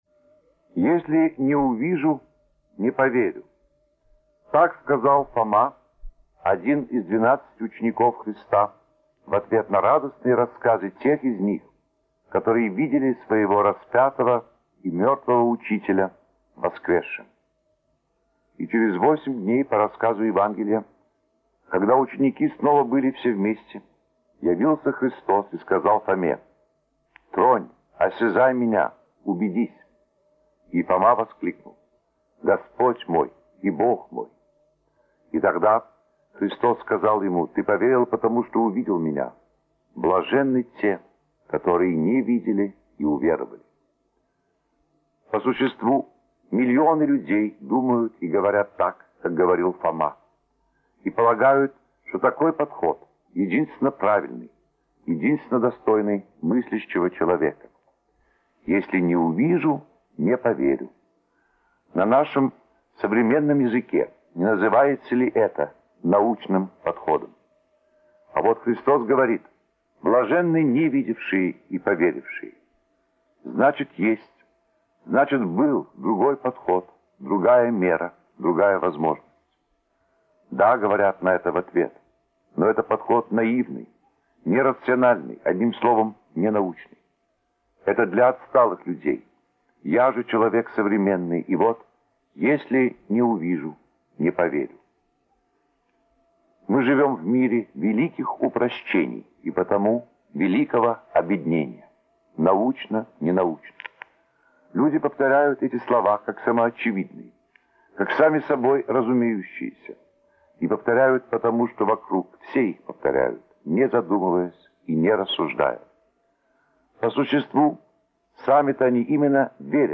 Аудиобеседы на 10-20 минут, сопровождаемые текстом — можно успеть прослушать даже между делом.